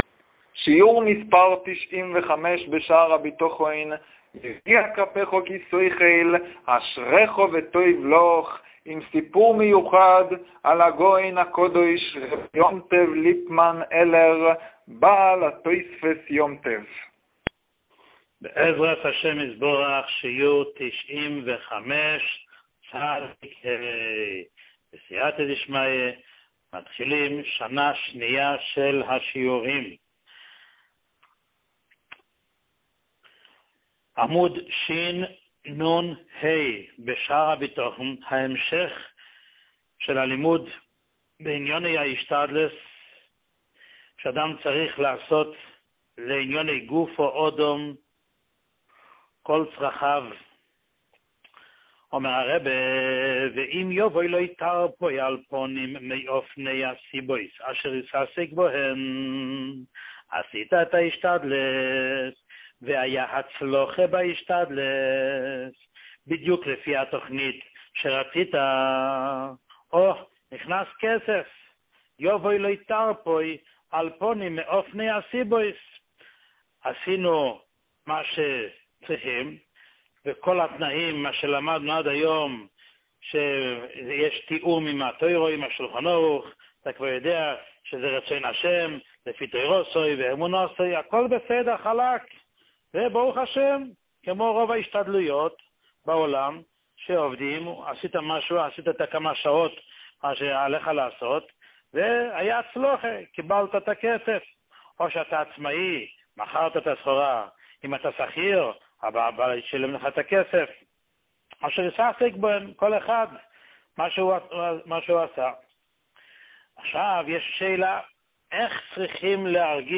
שיעור 95